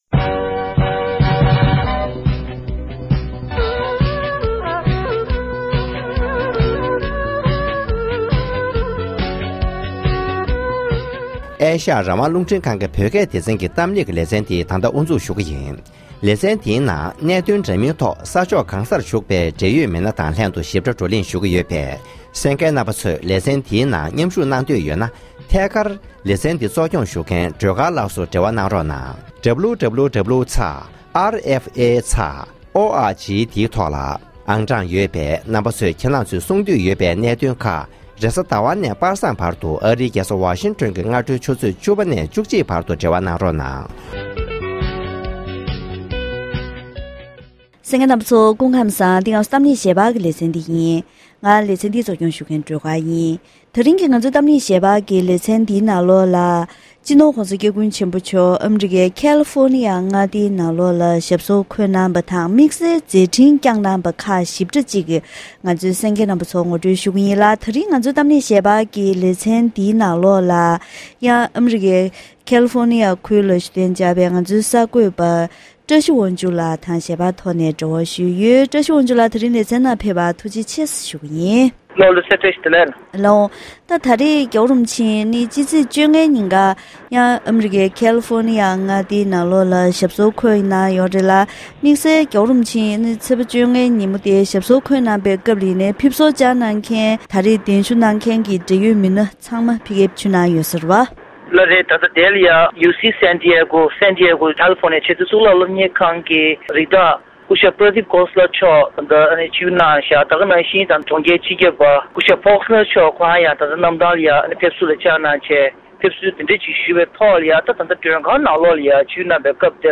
༸གོང་ས་༸སྐྱབས་མགོན་ཆེན་པོ་མཆོག་ནས་ཚེས་༡༧ཉིན་སློབ་ཐོན་མདད་སྒོར་བཀའ་སློབ་གནང་བ།